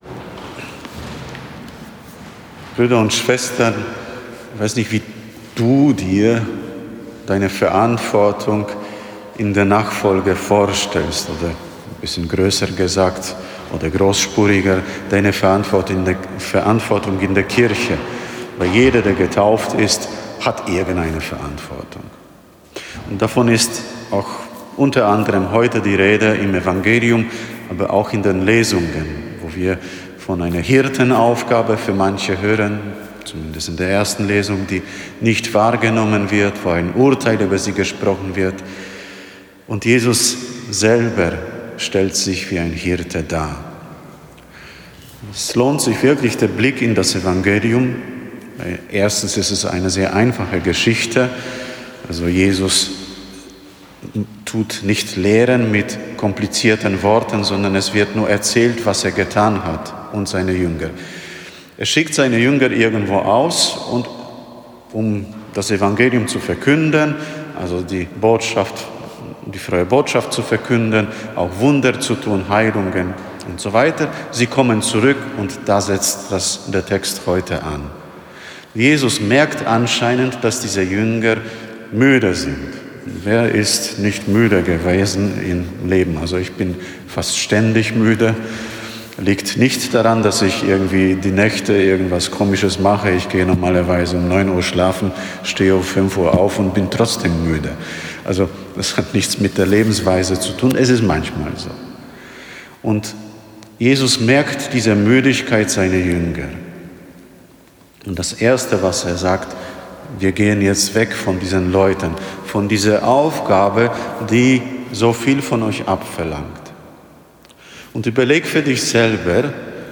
Eine Predigt zum 16. Sonntag im Jahreskreis B